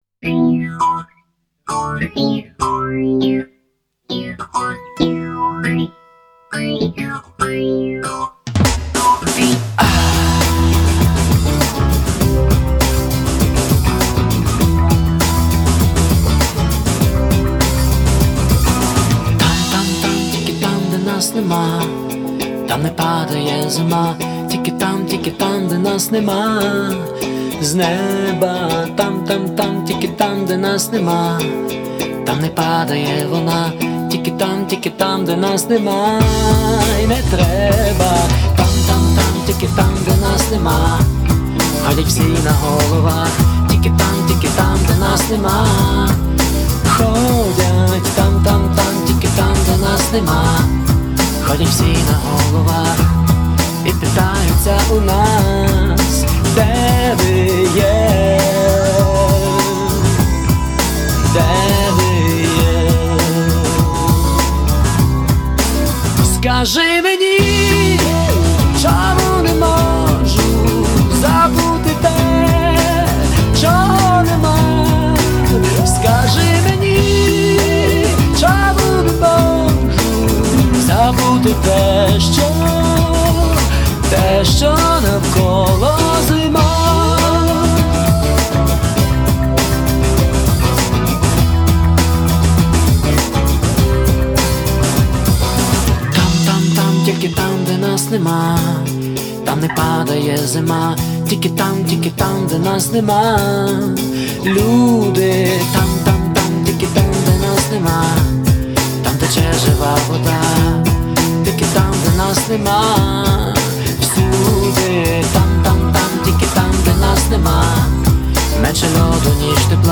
Рубрика: Рок